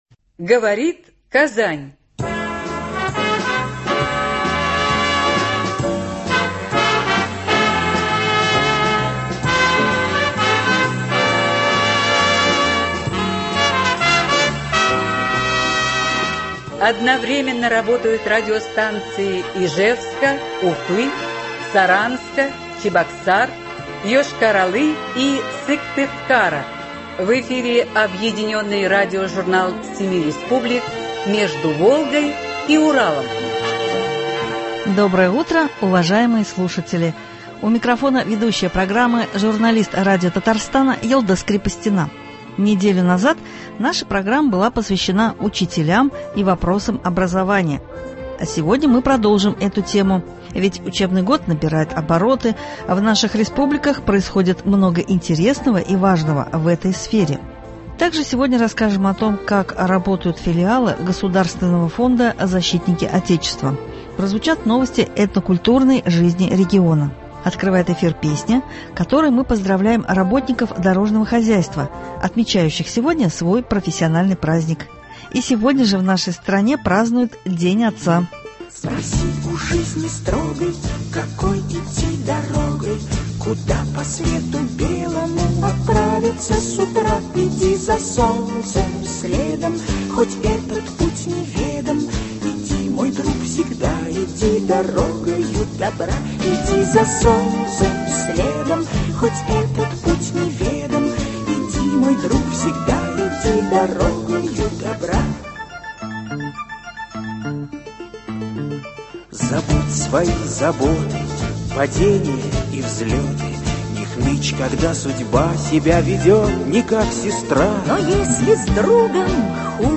Объединенный радиожурнал семи республик.
Открывает эфир песня , которой мы поздравляем работников дорожного хозяйства, отмечающих сегодня свой профессиональный праздник .